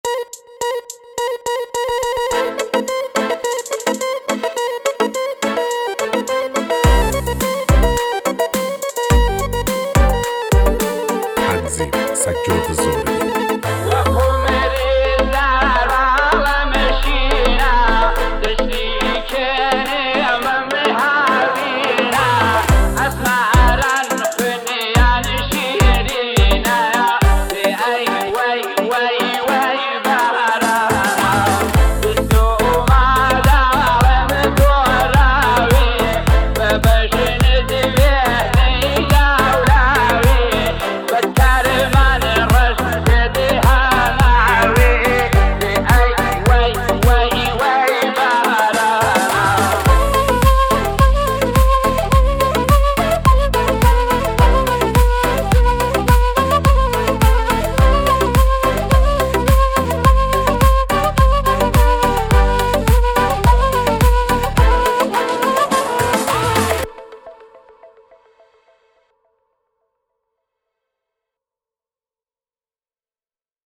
1,029 بازدید ۱۹ مهر ۱۴۰۲ ریمیکس , ریمیکس کردی